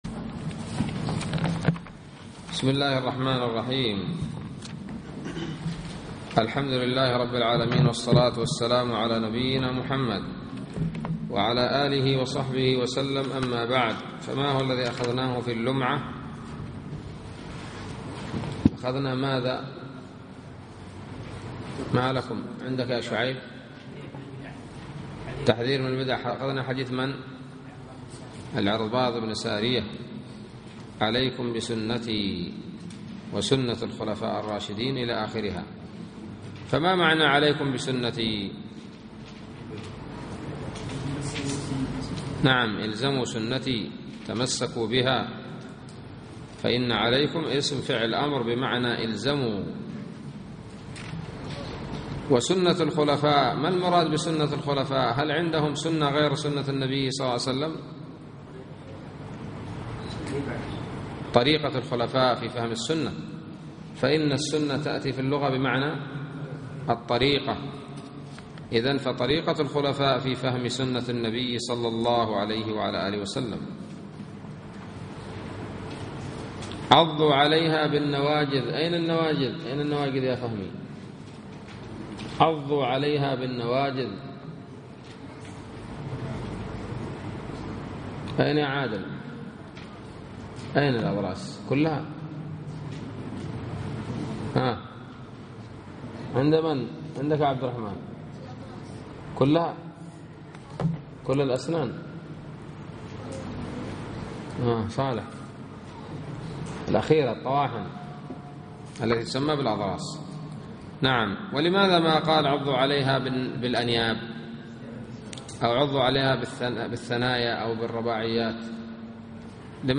الدرس الثامن من شرح لمعة الاعتقاد